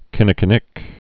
(kĭnĭ-kĭ-nĭk)